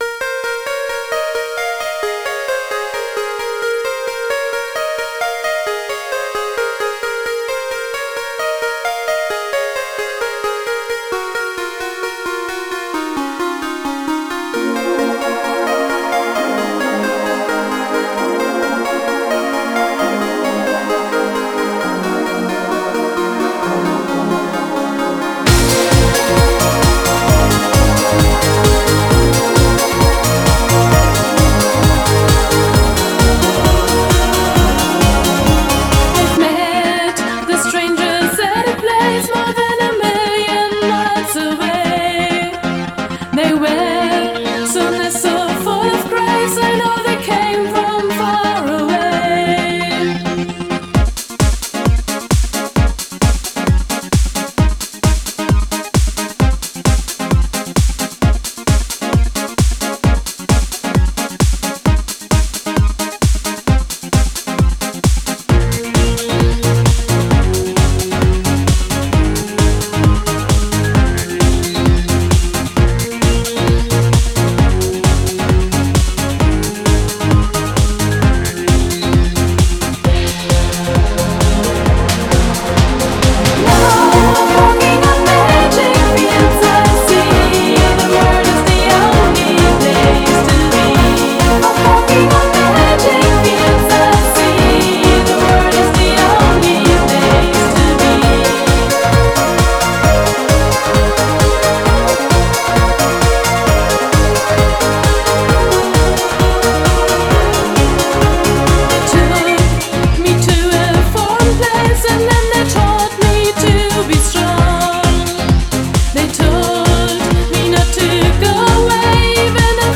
Genre: Synth-Pop.